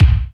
27.08 KICK.wav